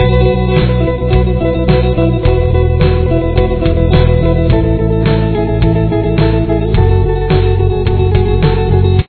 Verse Riff
The hardest part about this riff is keeping the steady pace of 16th notes (4 notes per beat) across both the B and G strings throughout the riff.
The first and second measures are identical. This song is in 4/4 timing.